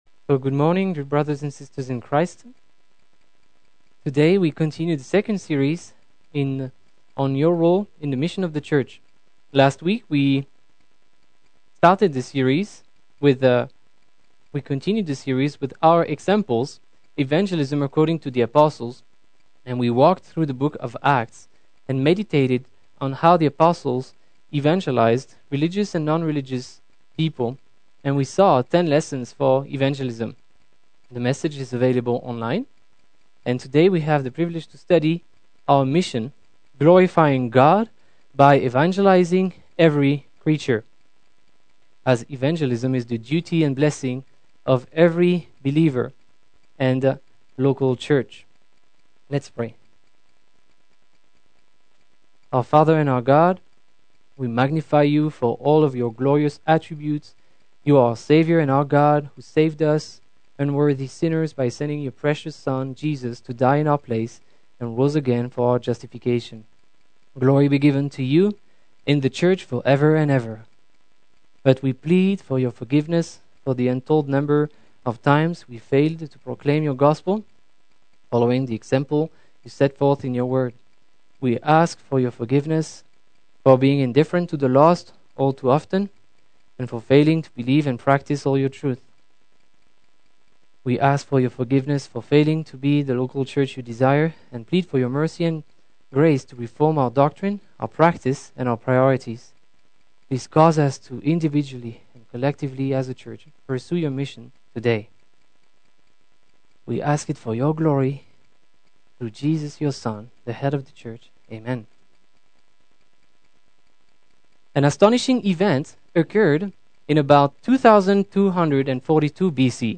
Summer Sunday School - 07/15/18